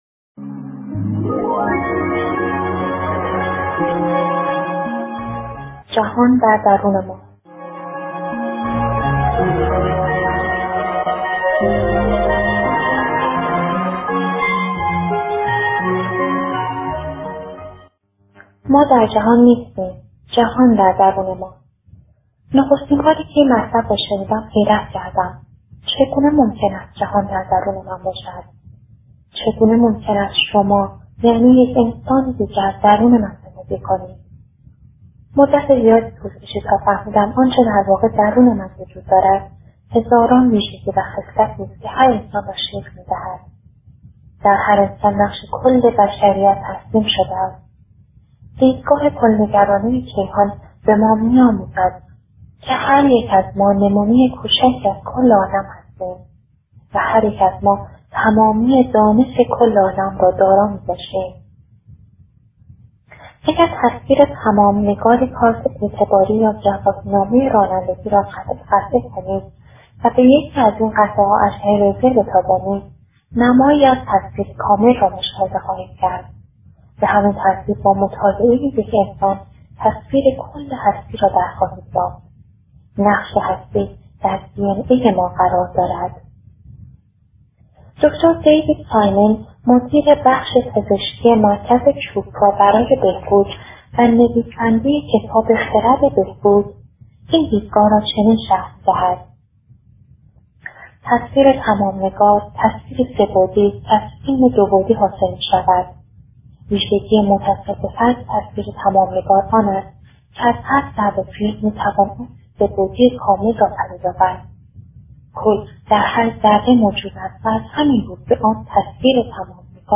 کتاب صوتی نیمه تاریک وجود